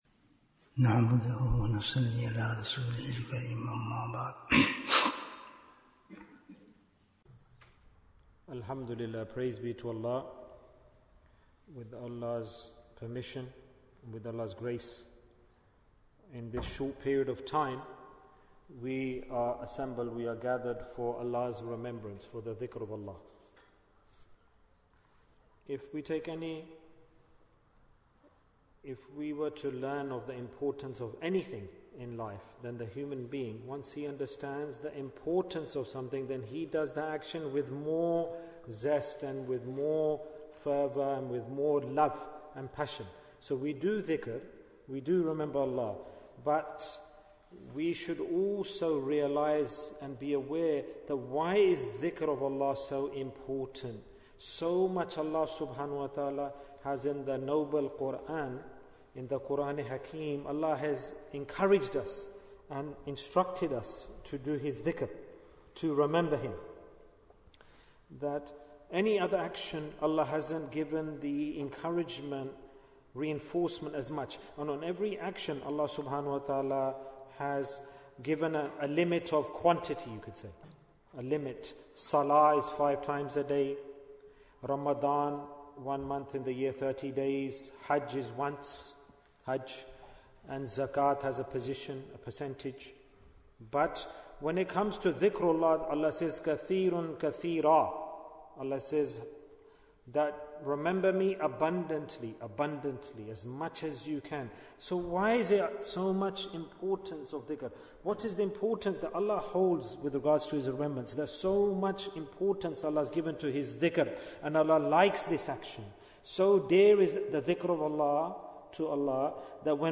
Bayan, 40 minutes23rd May, 2024